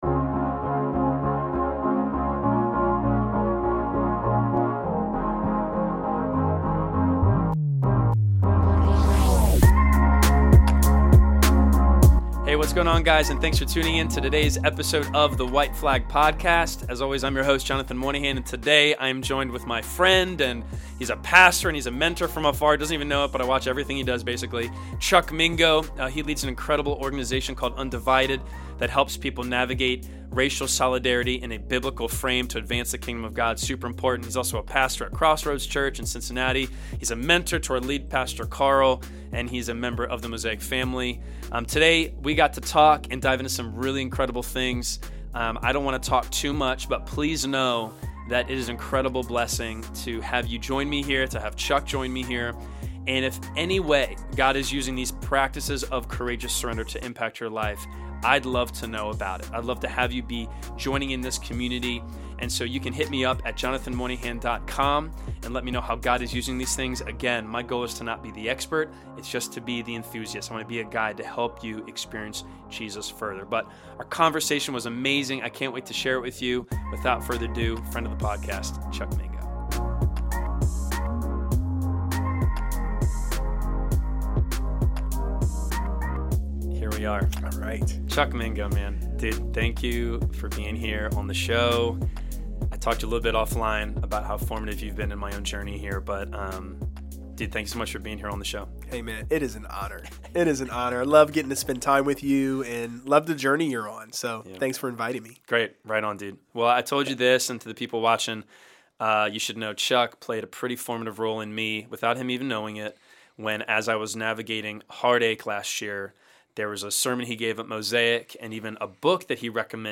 13. Interview